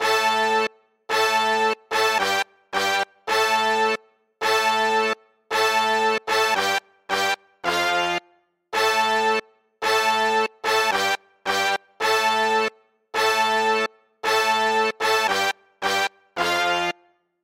黄铜部分 002
描述：一个与铜管乐器有关的部分
Tag: 110 bpm Hip Hop Loops Brass Loops 2.94 MB wav Key : Unknown